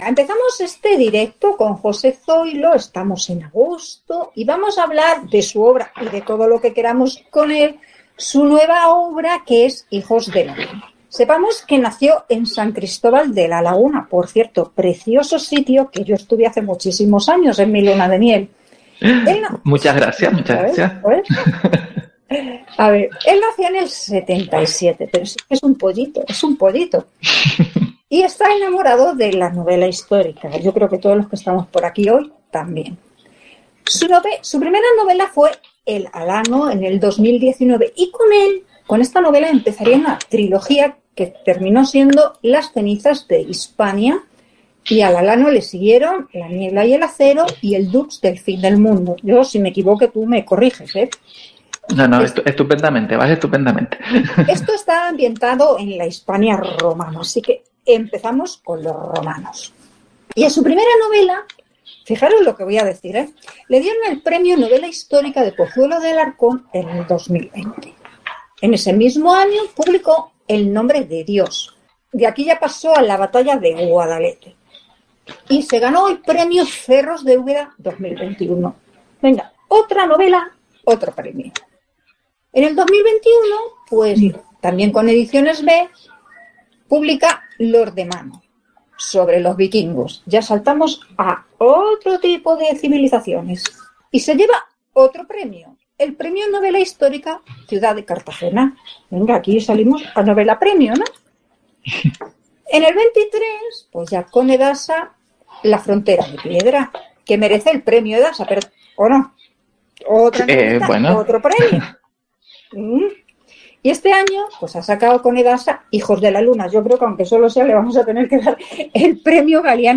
el Directo sobre Hijos de la luna en el canal de Telegram